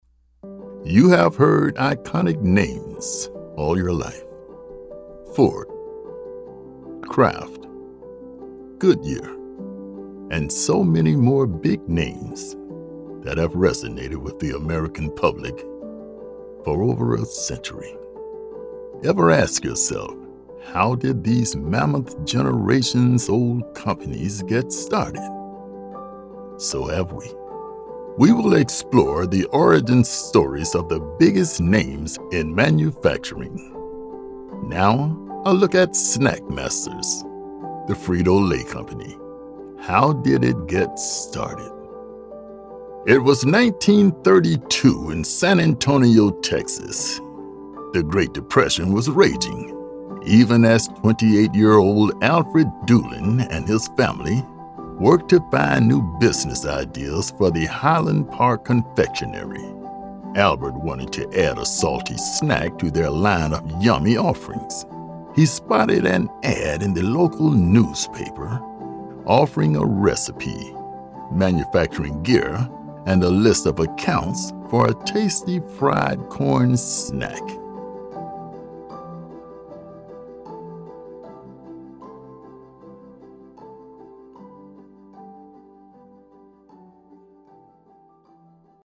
With a deep, resonant male voice that is both relaxed and sonorous, I deliver authoritative narrations, persuasive commercials, clear corporate explainers, and engaging eLearning content....
0316DOCUMENTARY--FRITO--LAY--CD.mp3